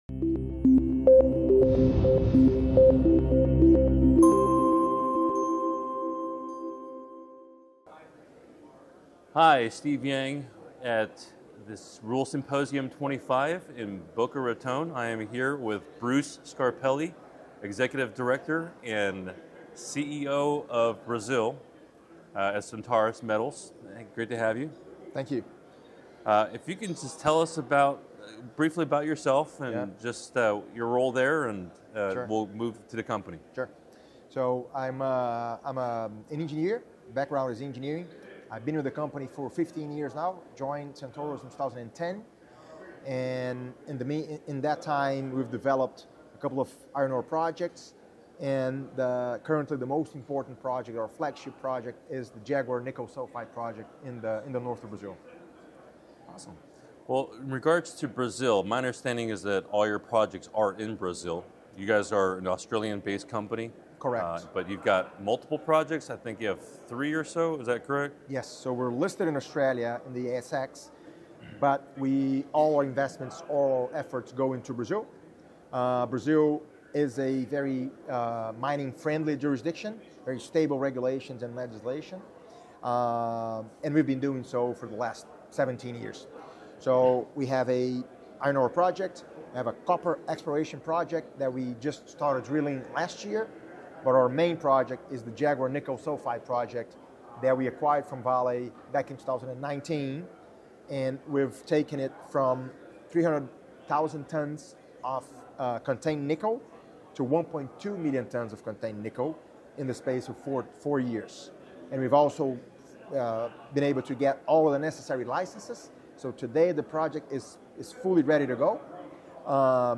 Natural Resource Stocks Interview